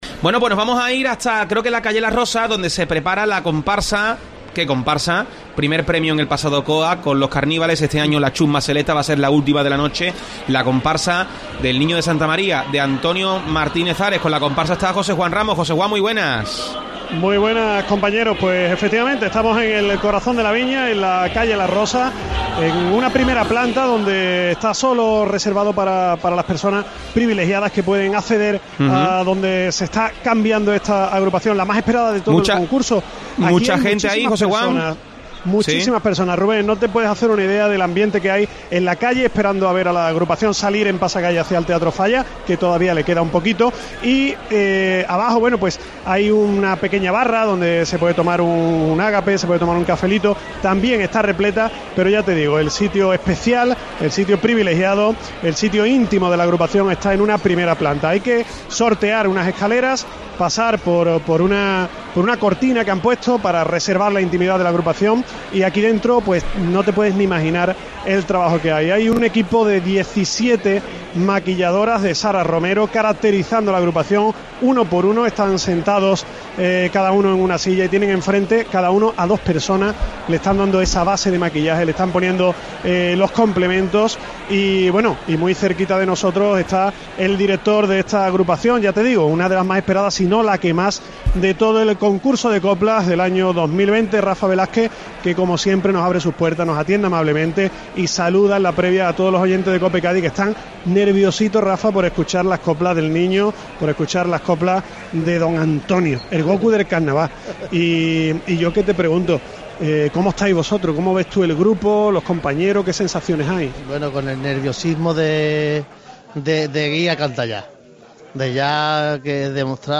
Redacción digital Madrid - Publicado el 30 ene 2020, 21:18 - Actualizado 16 mar 2023, 17:12 1 min lectura Descargar Facebook Twitter Whatsapp Telegram Enviar por email Copiar enlace La Cadena COPE en el local donde se prepara la comparsa de Antonio Martínez Ares 'La chusma selecta'. Escucha la entrevista con el autor e integrantes de la comparsa